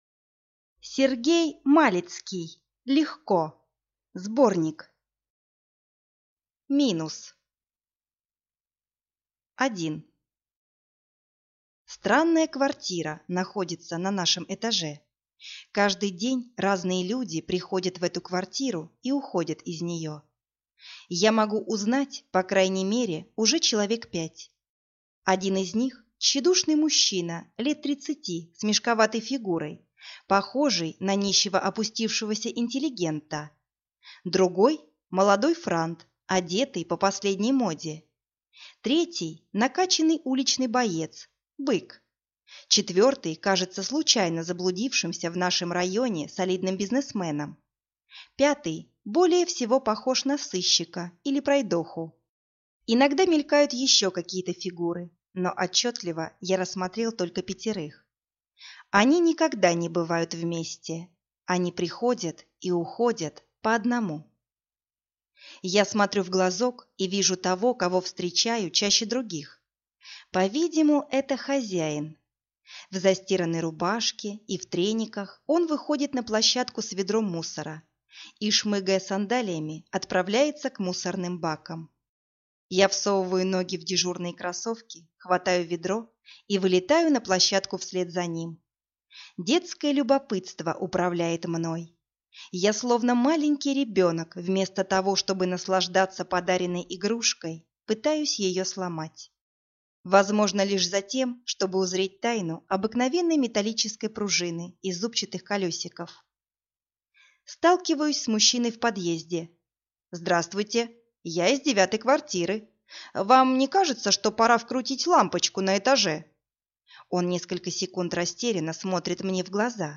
Аудиокнига Легко (сборник) | Библиотека аудиокниг